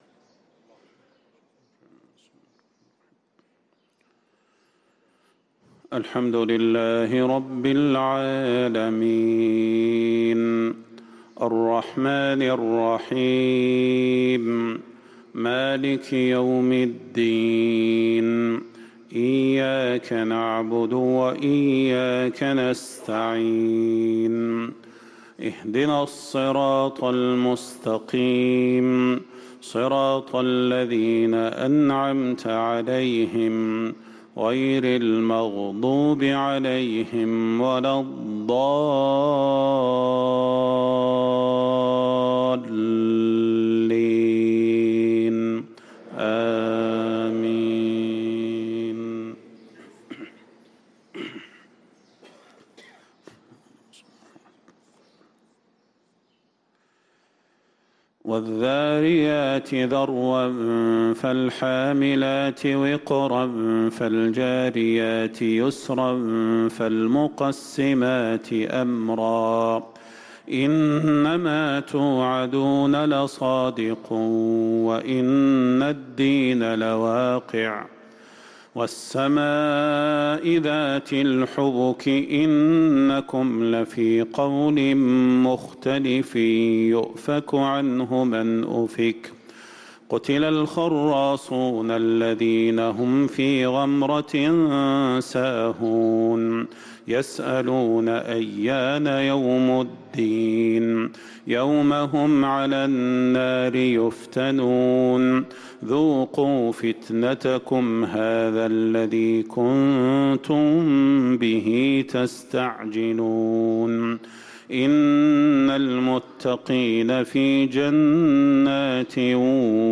صلاة الفجر للقارئ صلاح البدير 26 جمادي الأول 1445 هـ
تِلَاوَات الْحَرَمَيْن .